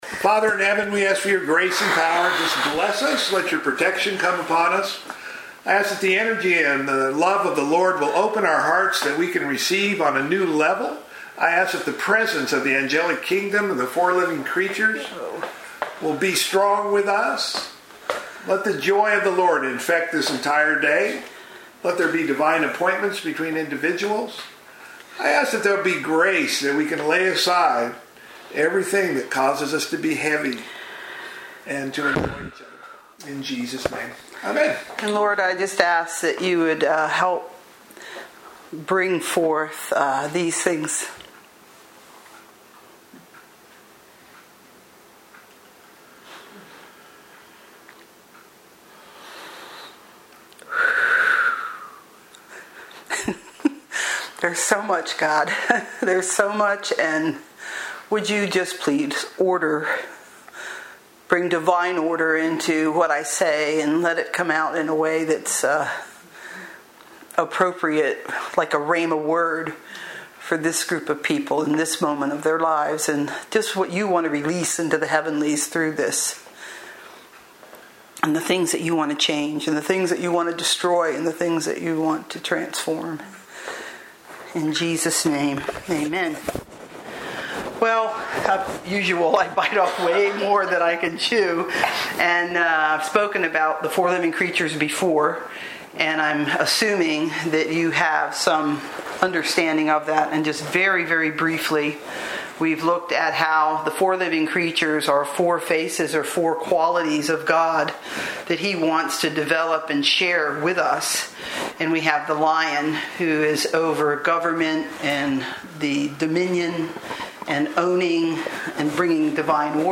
Lesson 2: School of the Man Man's Desire